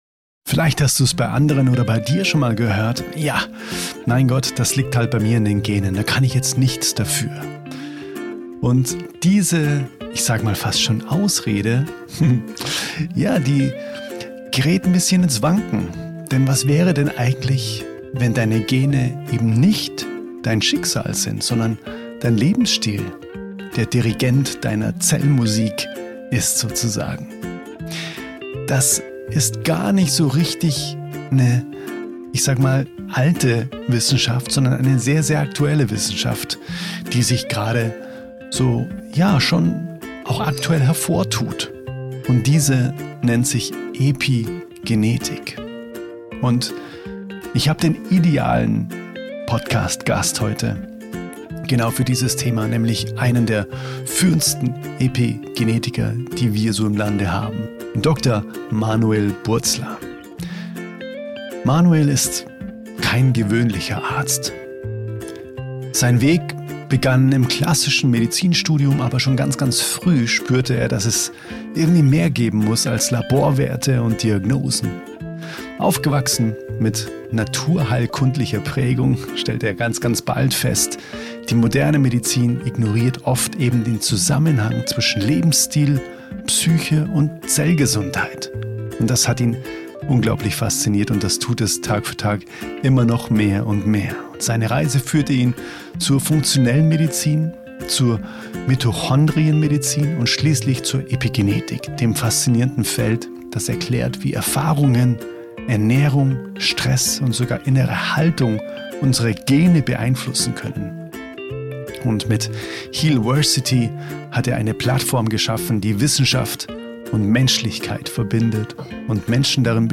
Ein Gespräch über Selbstverantwortung, Zellintelligenz – und die stille Kraft, die in uns allen wirkt.